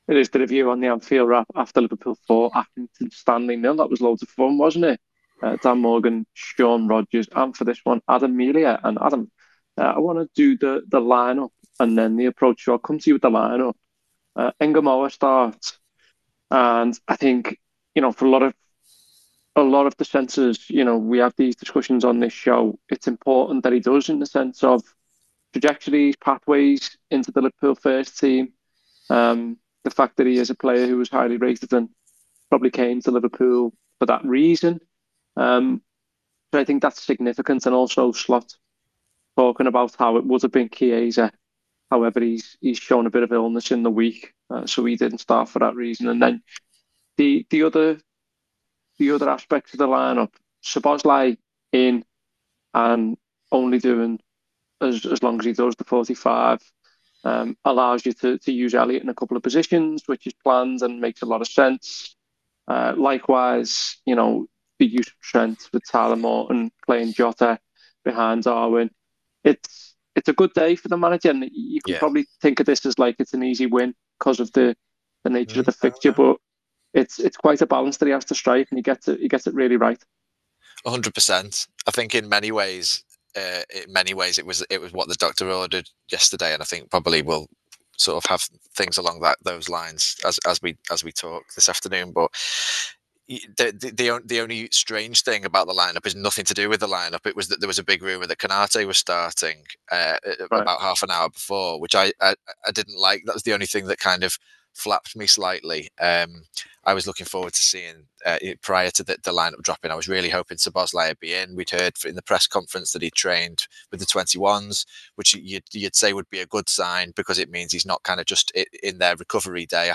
Below is a clip from the show – subscribe to The Anfield Wrap for more review chat around Liverpool 4 Accrington Stanley 0…